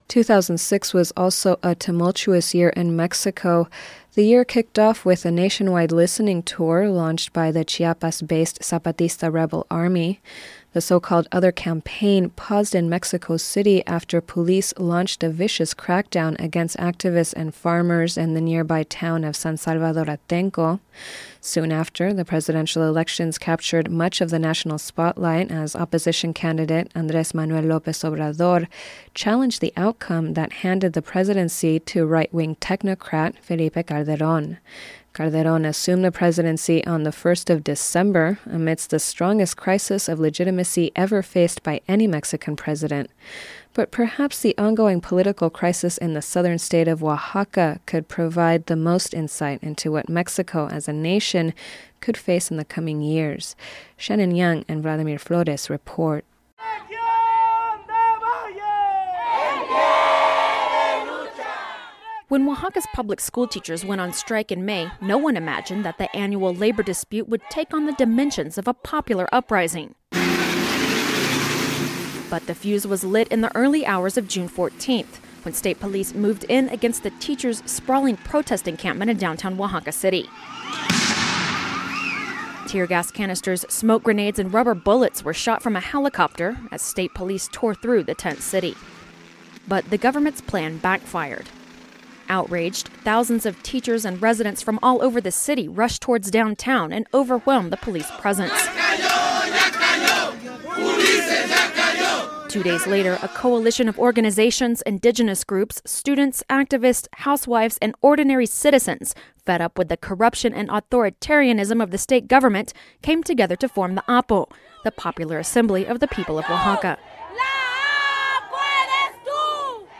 2006 year in review report on Oaxaca from FSRN